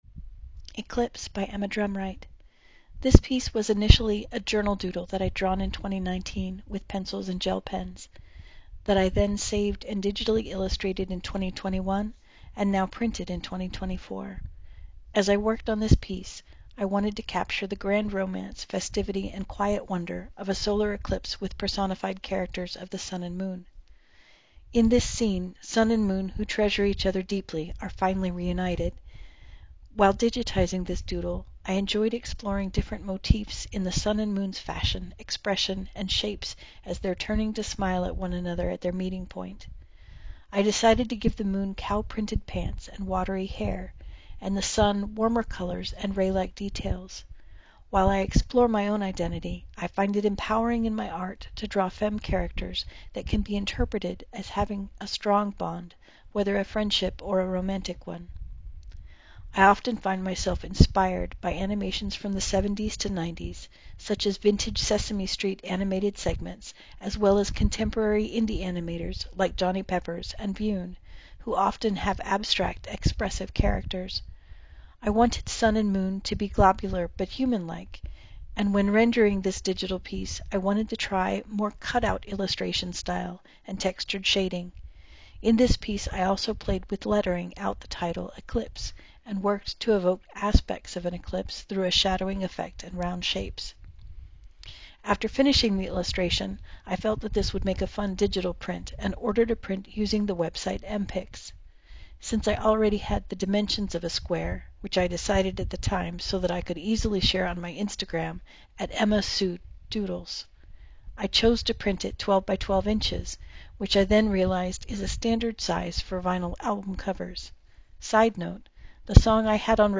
Audio recording of artist statement